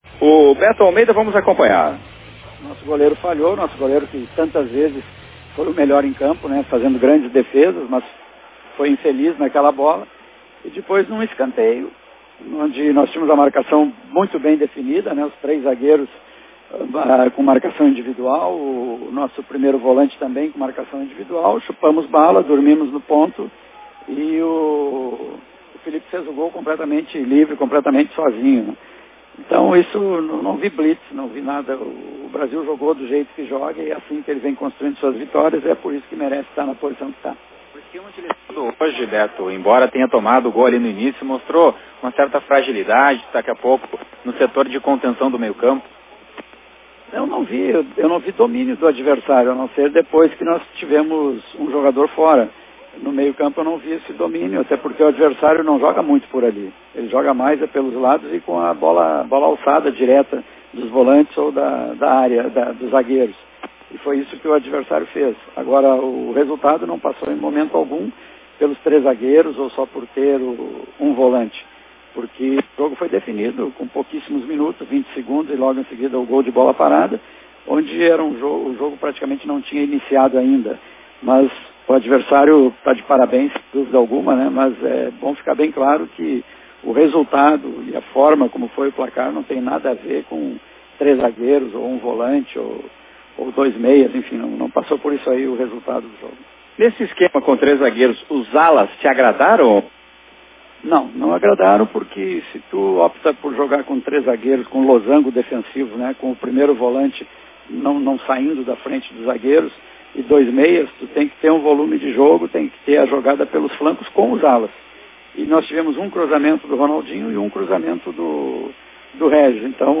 coletiva-brasil-x-união.mp3